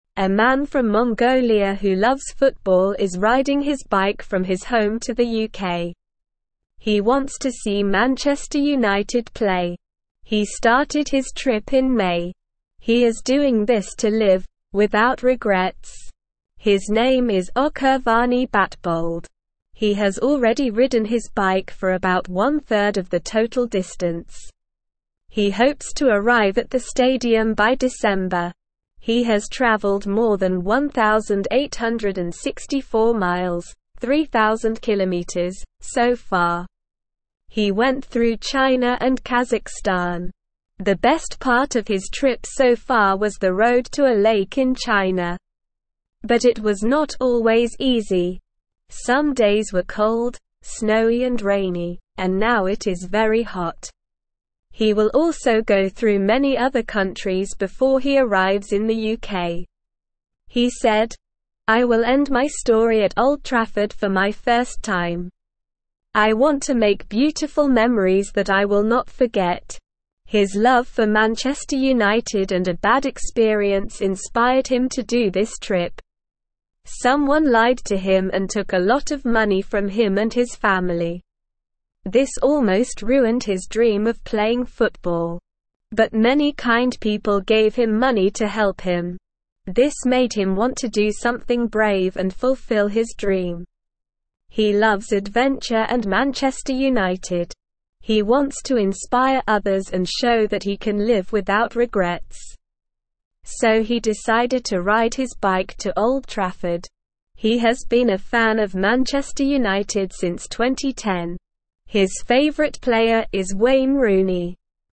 Slow
English-Newsroom-Beginner-SLOW-Reading-Man-Rides-Bike-to-UK-for-Football-Game.mp3